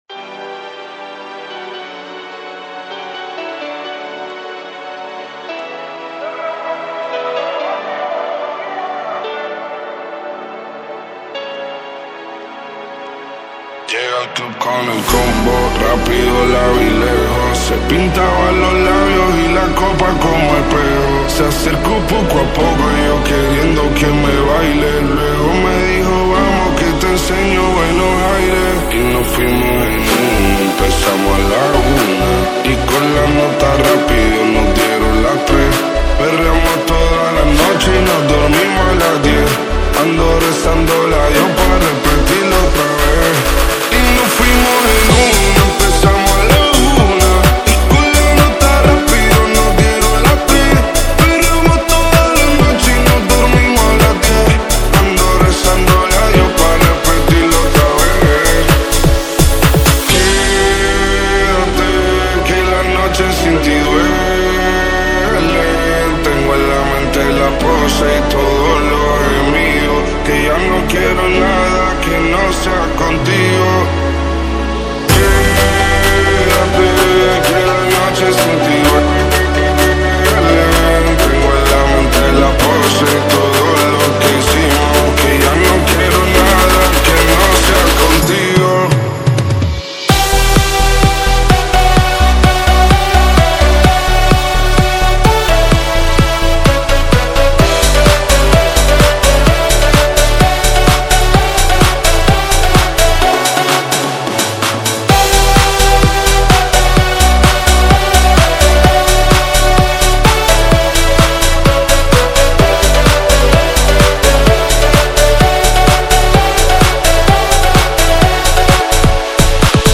Electronic Version